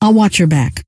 Index of /sc/sound/scientist_female/